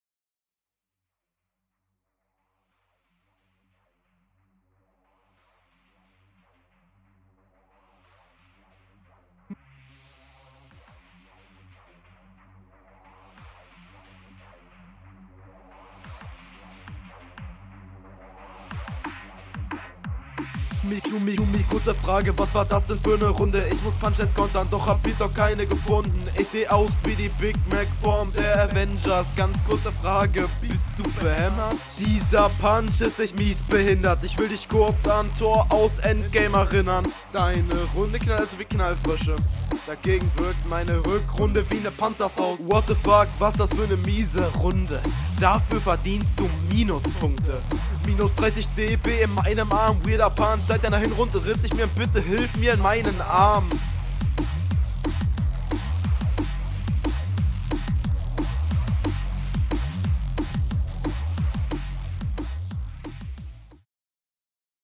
Runde ist ja mal todes leise gemischt.